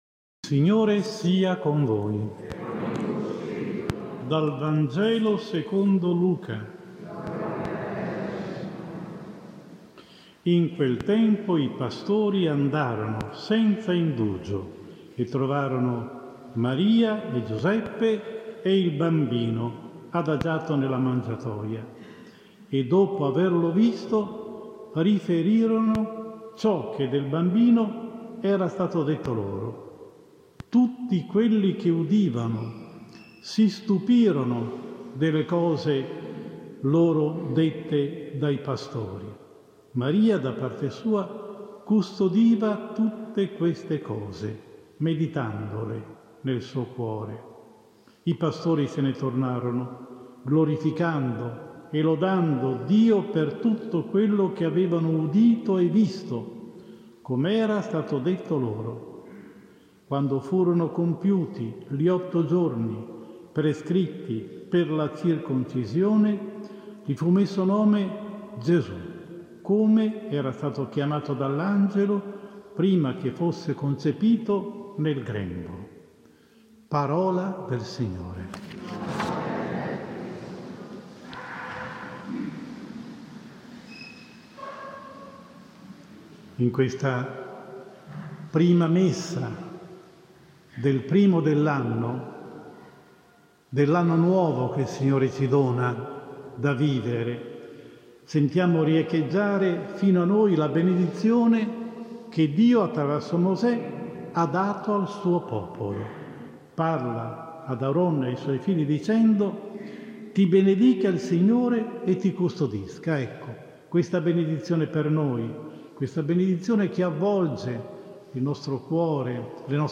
Domenica 24 Novembre 2019 XXXIV DOMENICA DEL TEMPO ORDINARIO (ANNO C) Nostro Signore Gesù Cristo Re dell’Universo – omelia